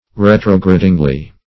Search Result for " retrogradingly" : The Collaborative International Dictionary of English v.0.48: Retrogradingly \Re"tro*gra`ding*ly\, adv. By retrograding; so as to retrograde.